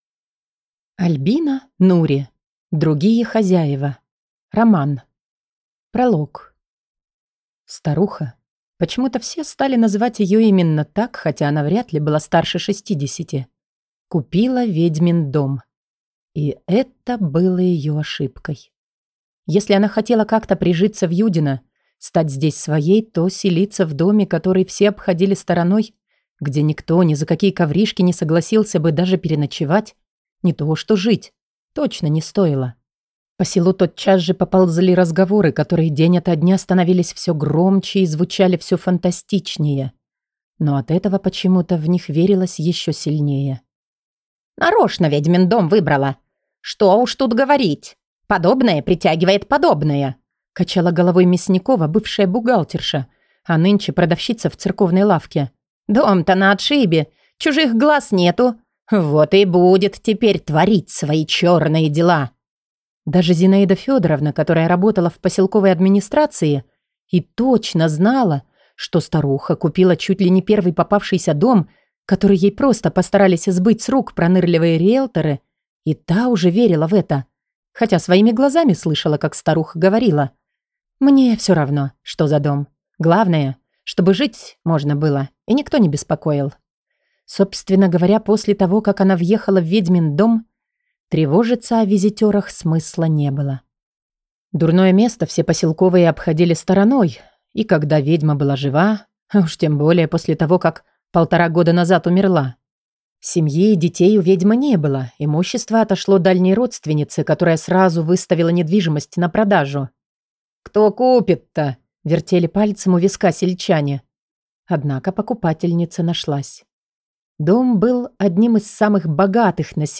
Аудиокнига Другие хозяева | Библиотека аудиокниг